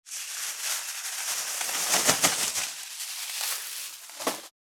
644コンビニ袋,ゴミ袋,スーパーの袋,袋,買い出しの音,ゴミ出しの音,袋を運ぶ音,
効果音